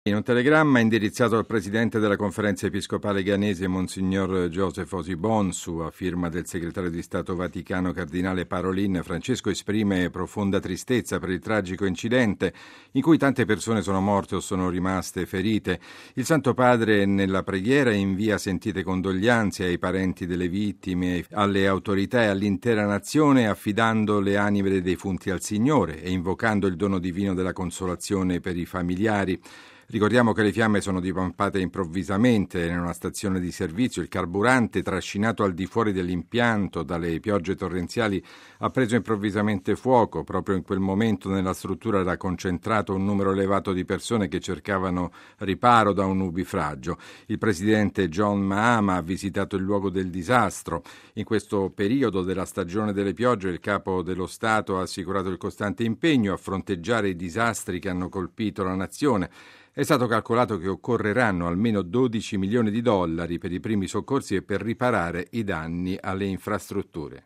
Un vasto incendio scoppiato in una stazione di rifornimento di benzina ha ucciso almeno 170 persone, che vi avevano trovato rifugio nella struttura. Il servizio